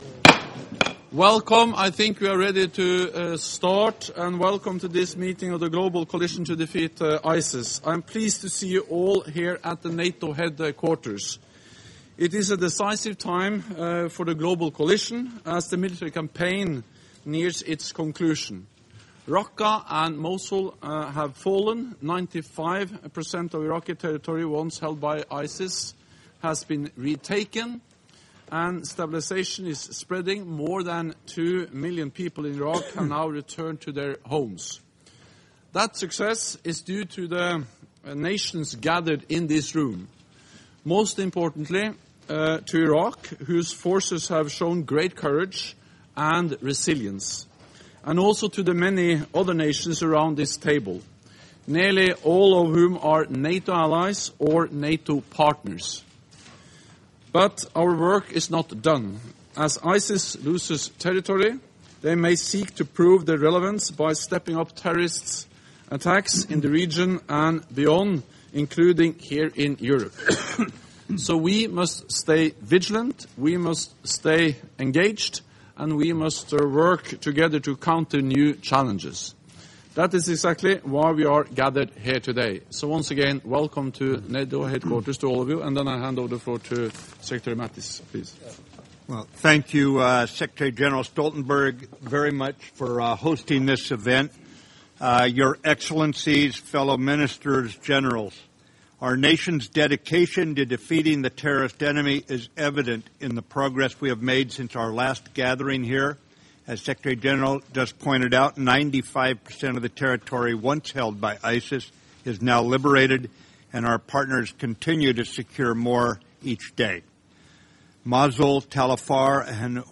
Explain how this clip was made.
(As delivered)